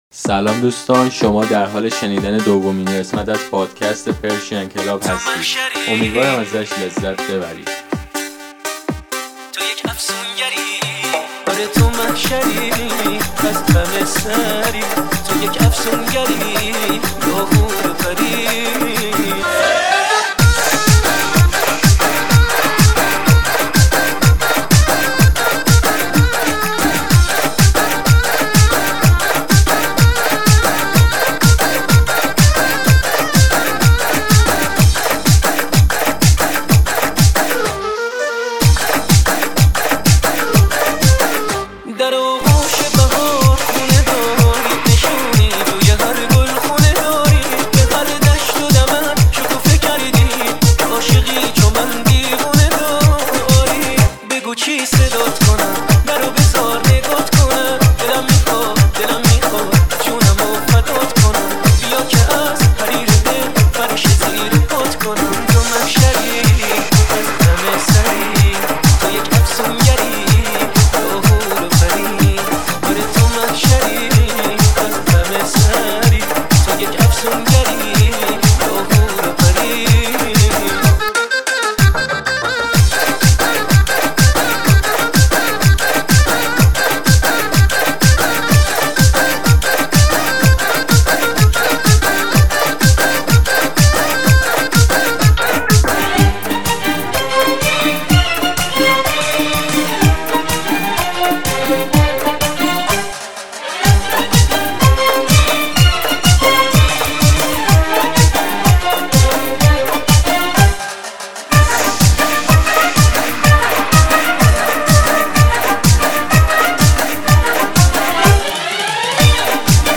گلچینی از بهترین آهنگ‌های رقصی برای ایجاد فضایی شاد و پرشور.